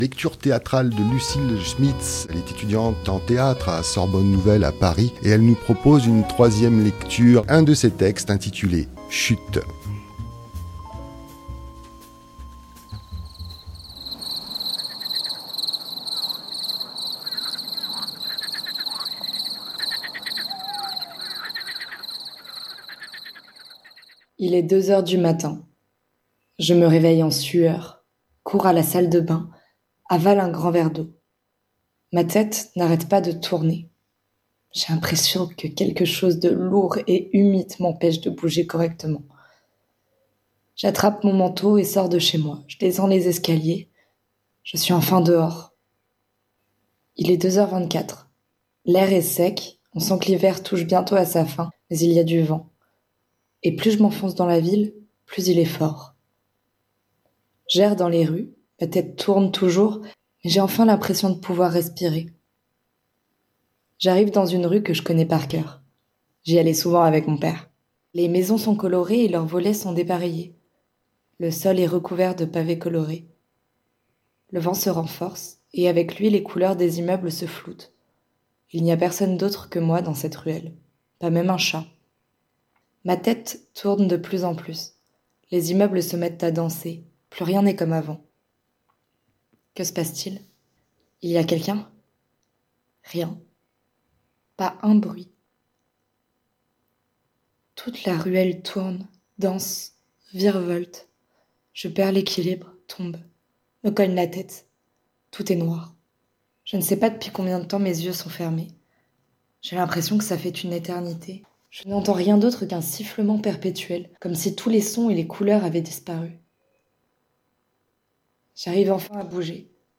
Lecture Théâtrale